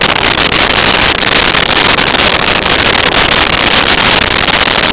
Sfx Amb Wind Metal Loop
sfx_amb_wind_metal_loop.wav